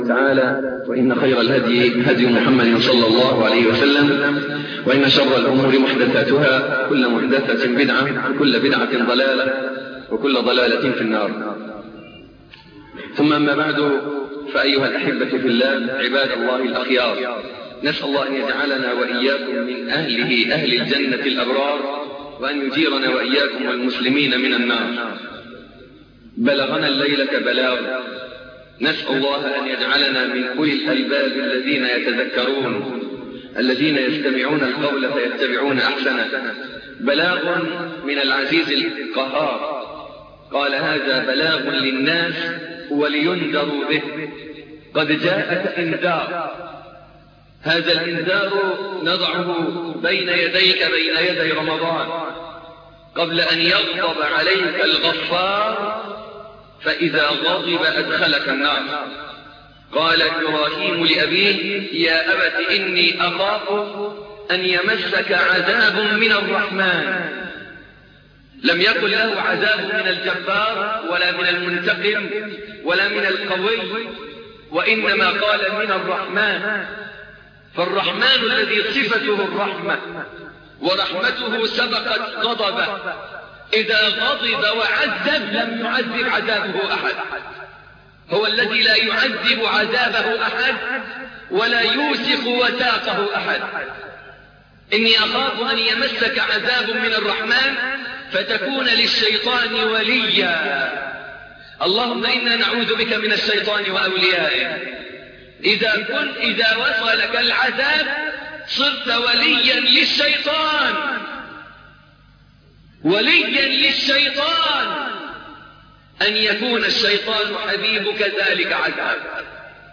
الدرس الأول - فضيلة الشيخ محمد حسين يعقوب